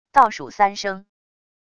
倒数三声wav音频